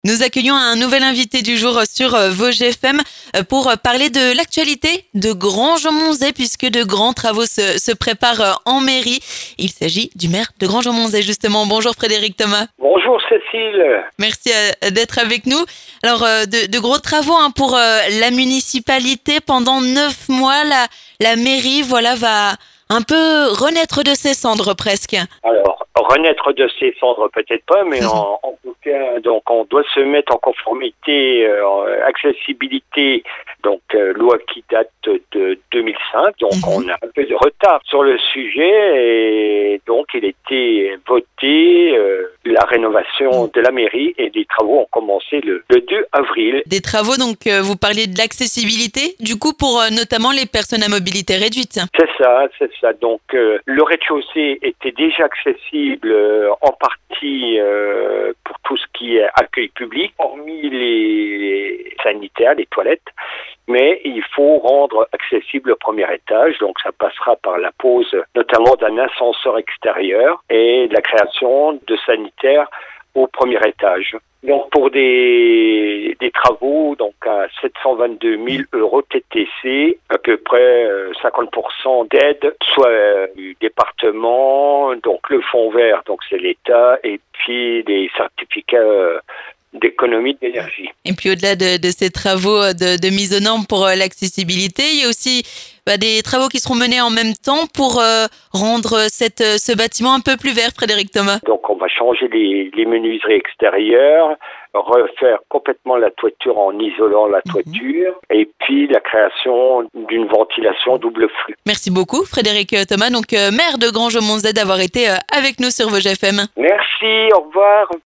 L'invité du jour
Toutes les infos sur ces travaux avec le maire de Granges-Aumontzey, Frédéric Thomas.